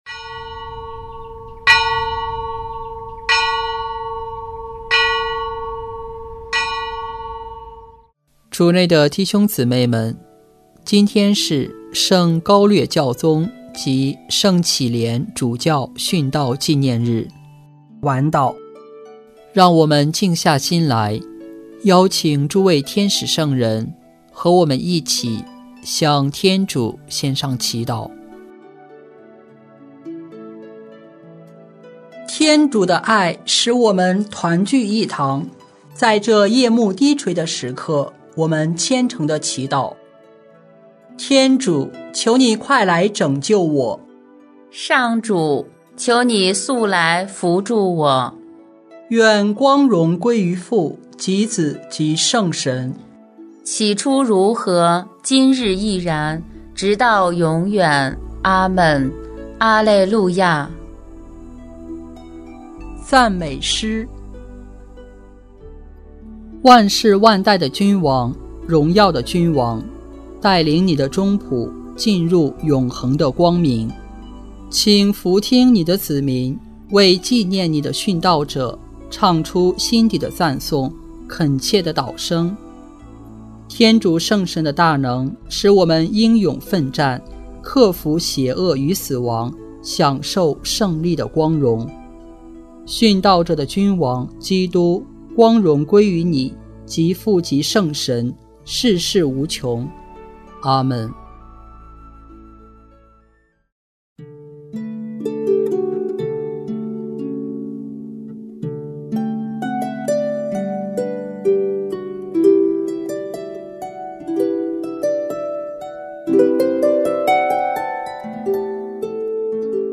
圣咏吟唱 圣咏 136:1-6 巴比伦河畔 “以民的被俘，应视为灵性被俘的写照。”